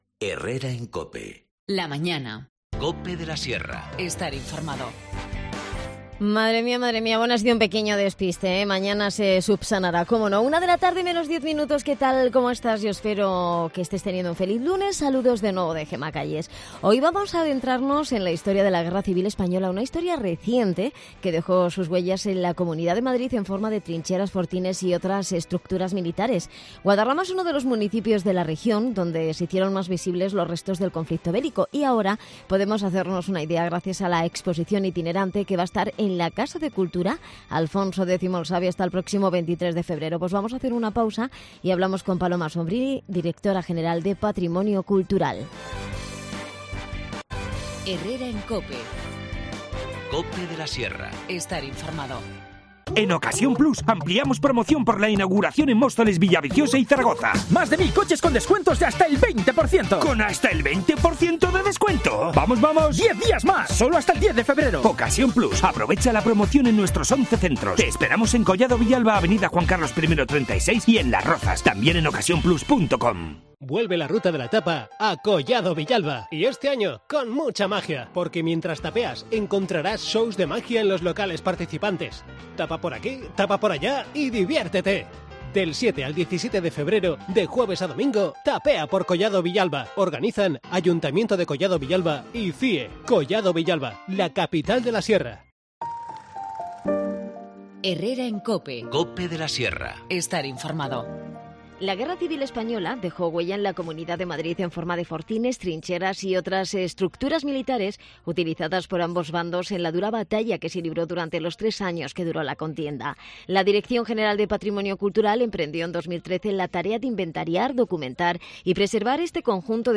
Hablamos con la directora general de Patrimonio Cultural de la Comunidad de Madrid, Paloma Sobrini, de la exposición "Entre fortines y trincheras: Plan de Fortificaciones de la Guerra Civil" que acaba de recalar en Guadarrama.